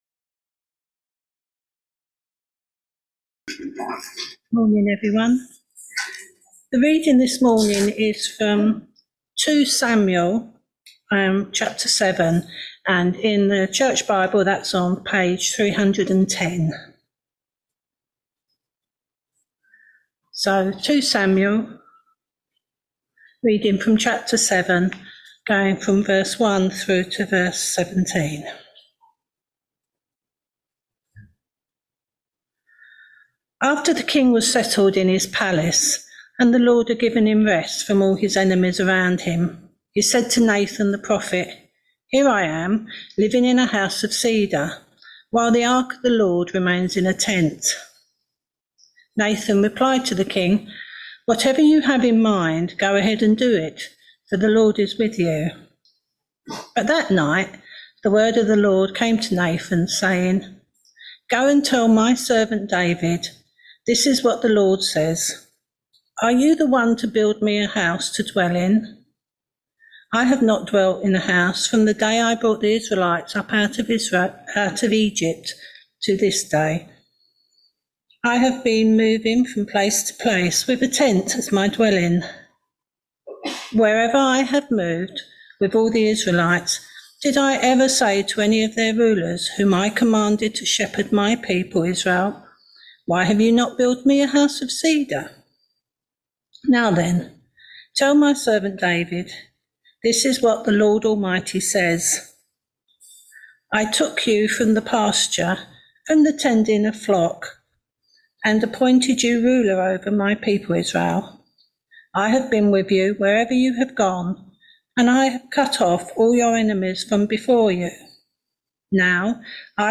Sunday Morning All Age Service Topics